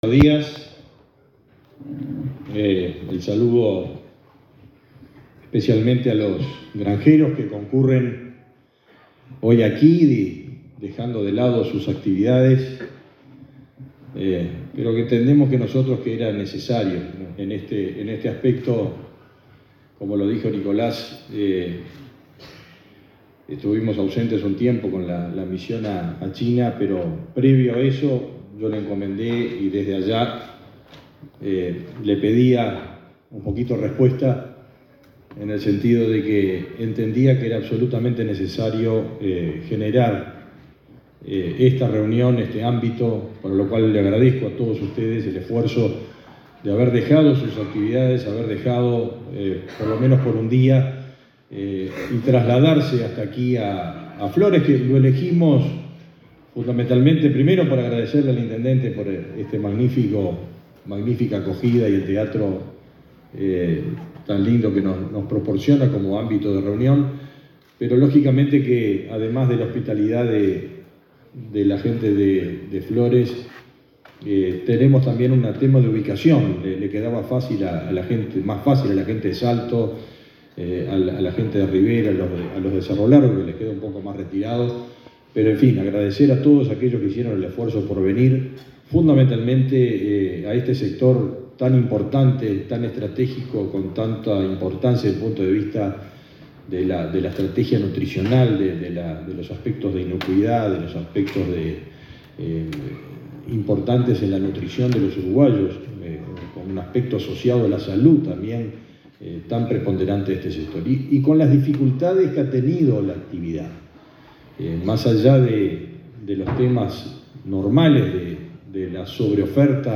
Palabras del ministro de Ganadería, Fernando Mattos
El ministro de Ganadería, Fernando Mattos, participó este miércoles 5 en el Primer Congreso Nacional de la Granja, que se lleva a cabo en la ciudad de